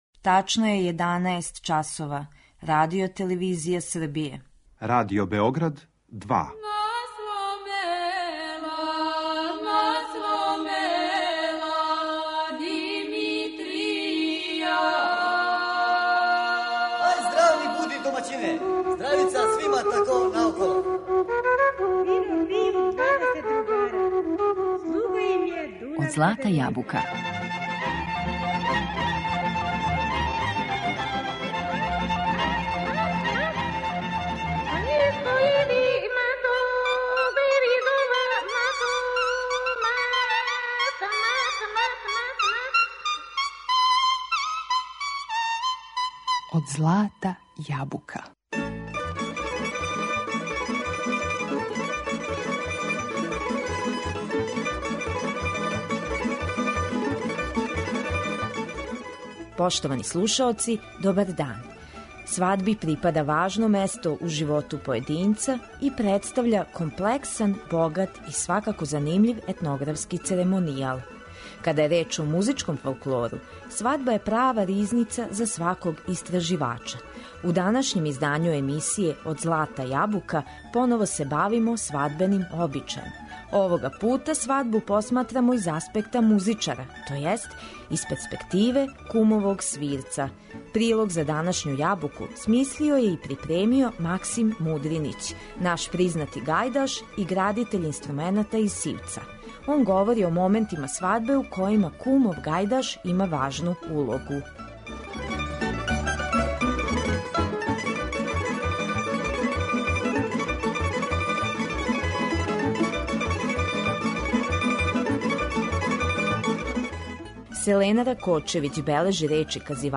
Зато у емисији слушамо сватовце и бећарце, као и по које коло, типично за војвођанску свадбу и војвођанске свирце.